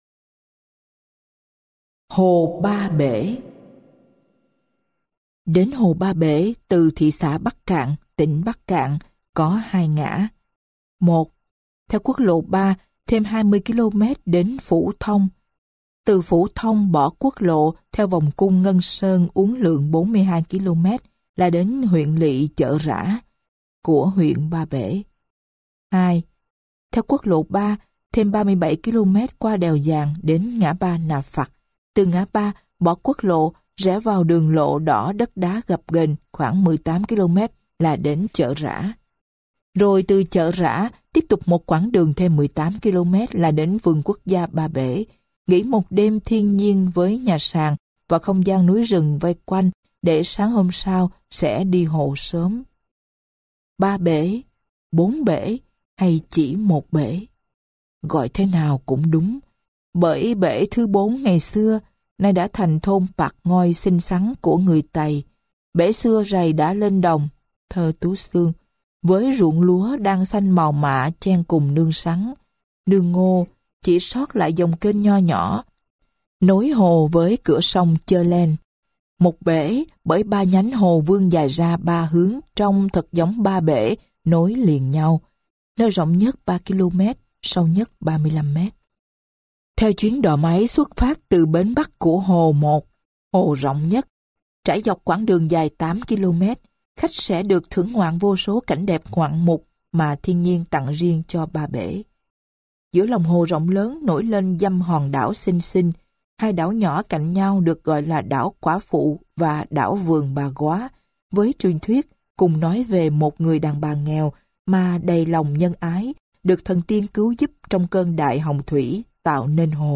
Sách nói Hồn Việt Nam trong mỗi chuyến đi - Sách Nói Online Hay